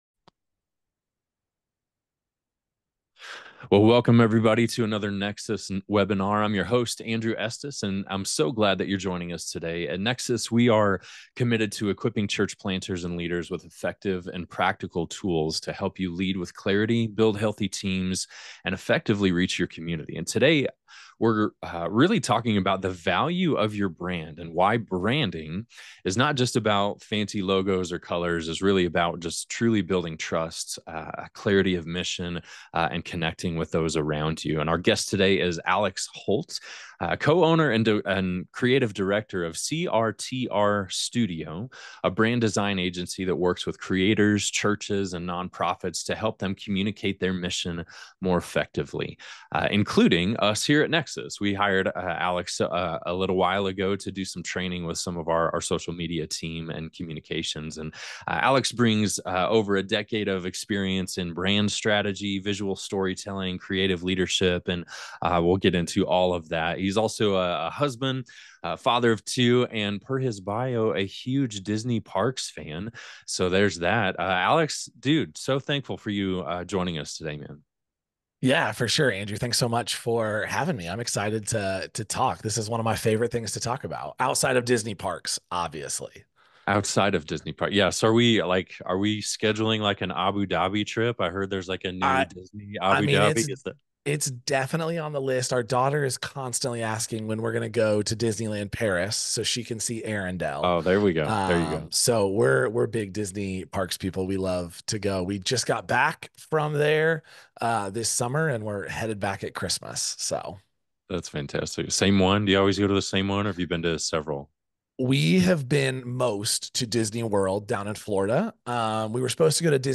Whether you’re launching a new church, leading a growing ministry, or simply trying to reach your community more effectively, this conversation will equip you with practical tools to build trust, clarify your message, and lead with purpose.